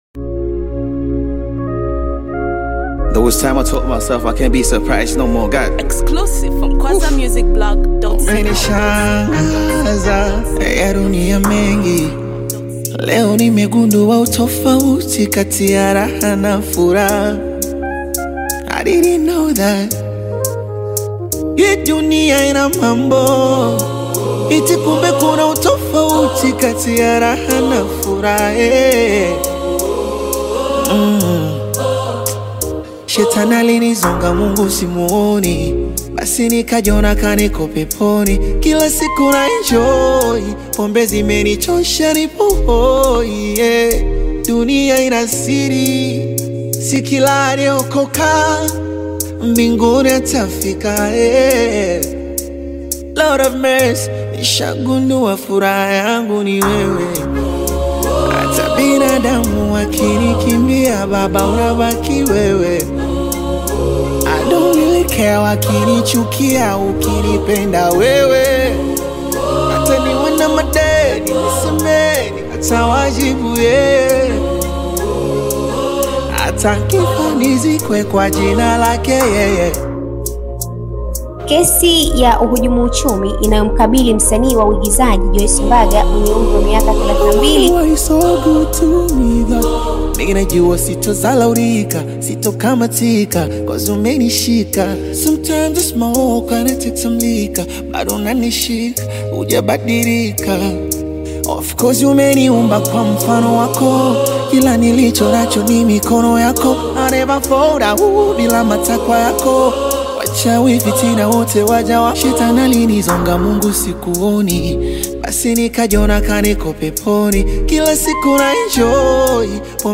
Blending Soulful Bongo Flava With Heartfelt Lyrics
Honest, Raw, And Beautifully Melodic